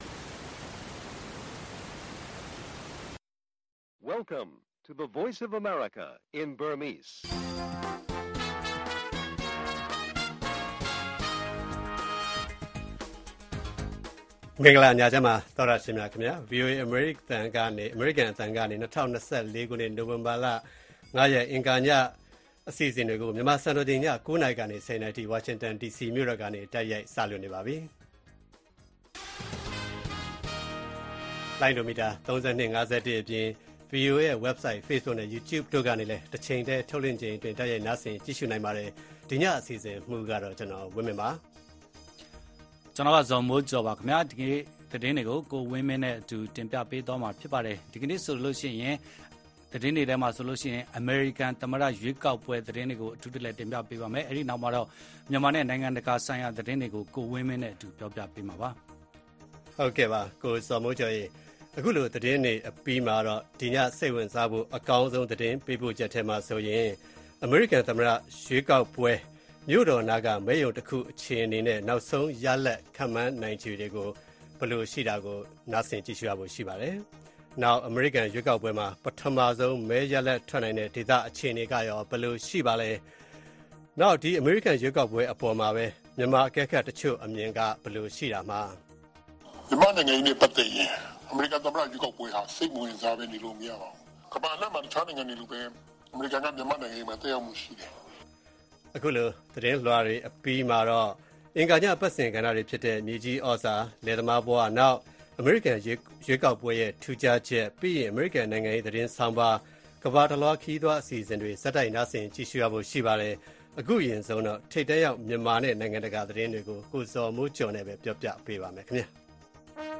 အမေရိကန် ရွေးကောက်ပွဲ တိုက်ရိုက်သတင်းပေးပို့ချက်၊ ဗိုလ်ချုပ်မှူးကြီး မင်းအောင်လှိုင် တရုတ်ခရီးစဉ်စတင် စတာတွေအပြင် မြေကြီးသြဇာ၊ အမေရိကန်နိုင်ငံရေး၊ ကမ္ဘာတလွှား ခရီးသွား အစီအစဉ်တွေကို တင်ဆက်ထားပါတယ်။